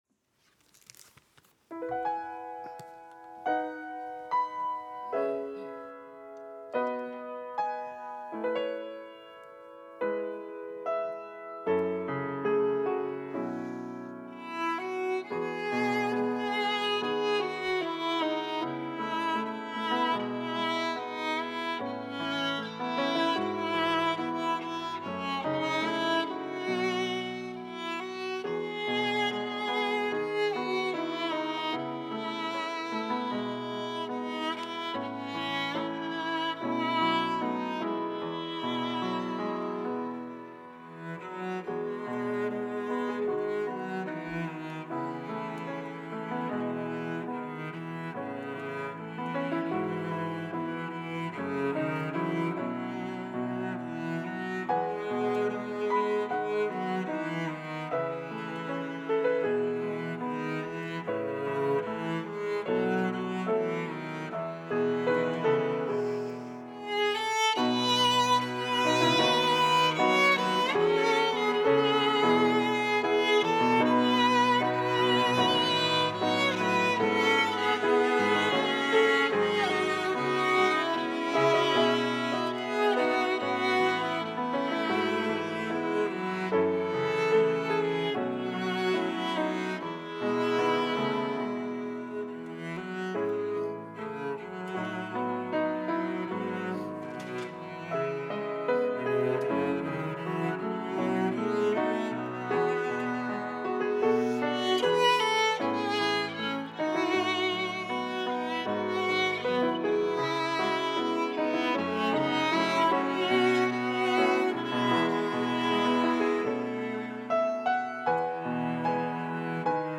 특송과 특주 - 주만 바라볼지라